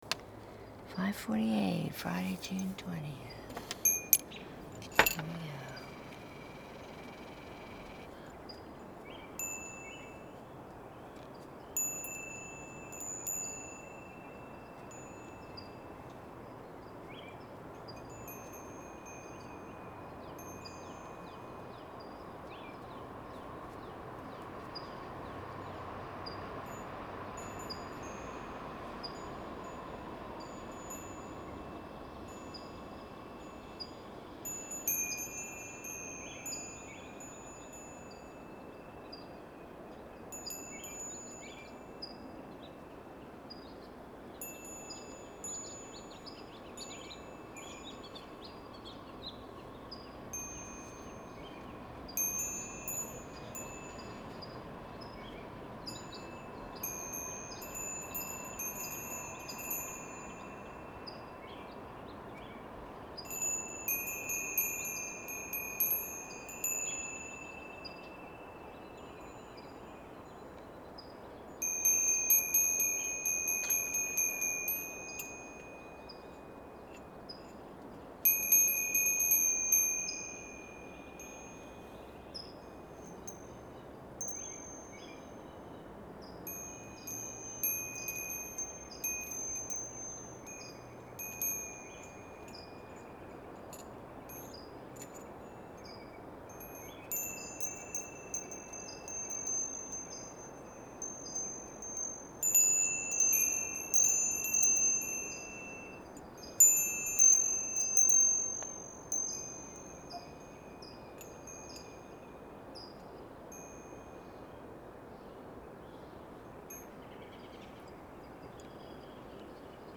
SUNRISE SOUNDSCAPES
audio recording is from the back yard.